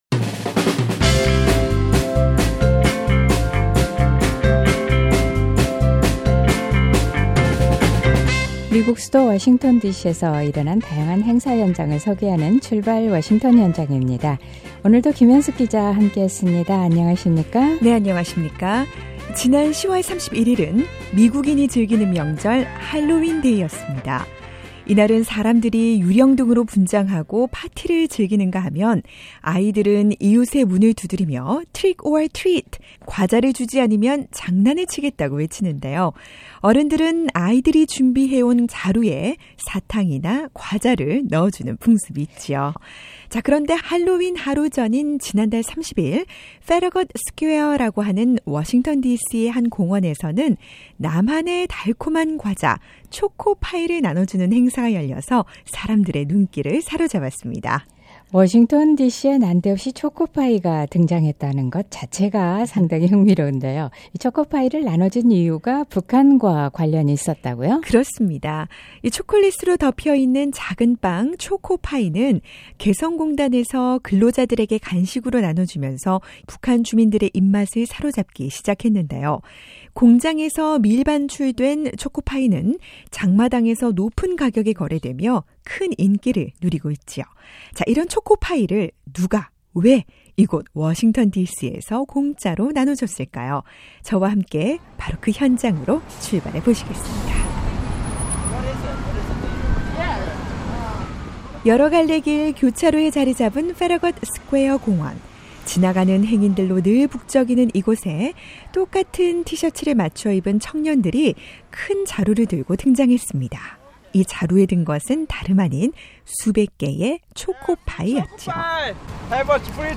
바로 이 초코파이를 통해 북한의 상황과 북한 인권문제를 알리기 위해 청년들이 길거리로 나선 겁니다. 오늘은 북한을 위한 초코파이 거리 나눔 현장으로 출발해봅니다.